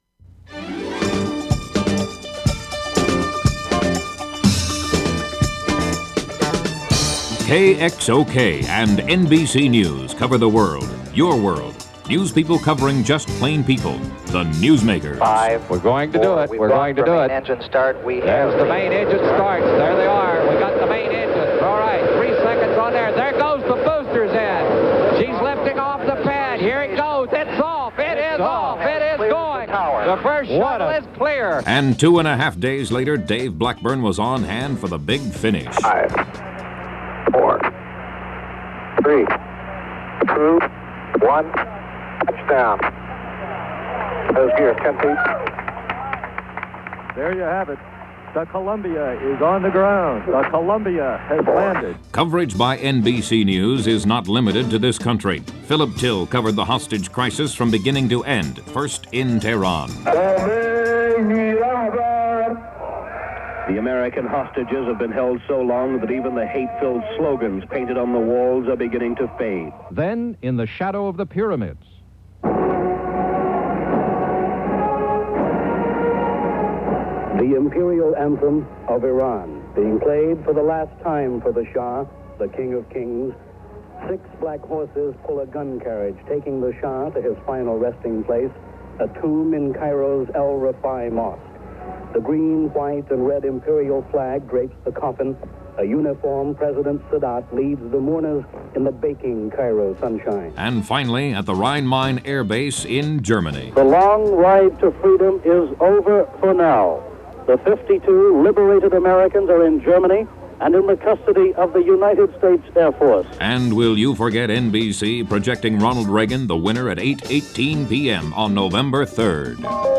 radio promo